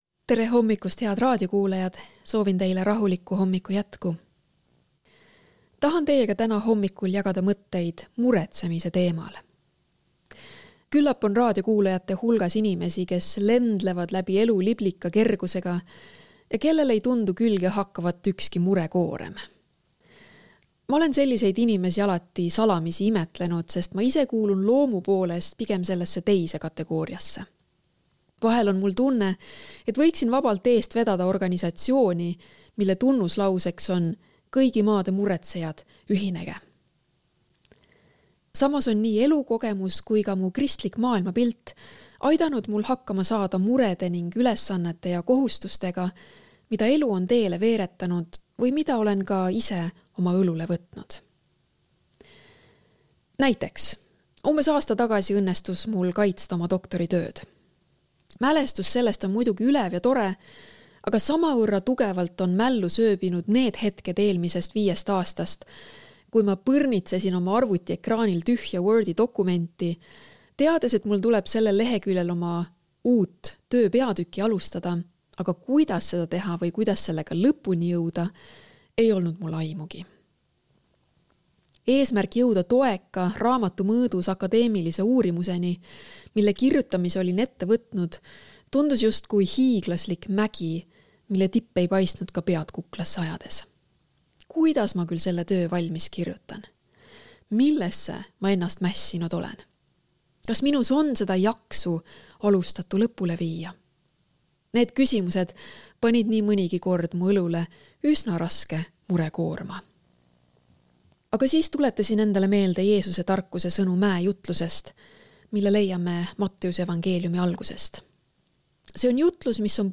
hommikupalvus ERR-is 28.06.2024